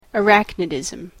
/əˈræknɪdɪzm(米国英語)/